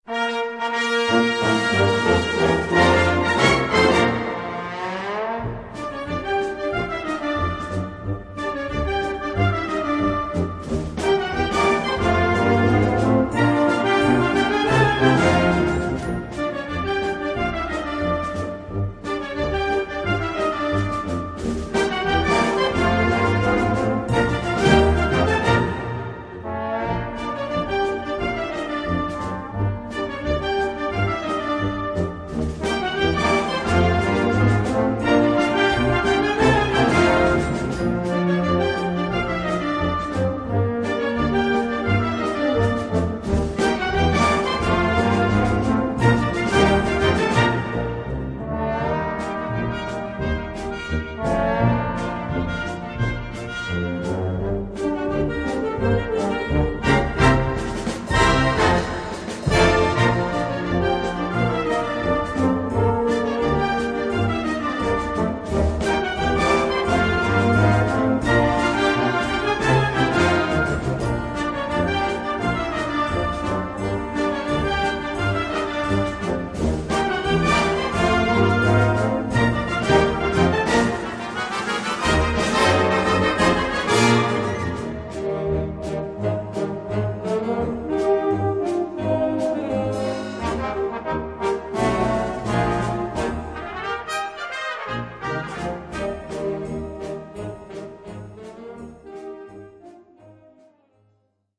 Gattung: Ragtime
Besetzung: Blasorchester
heiteres Stück Musik